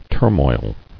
[tur·moil]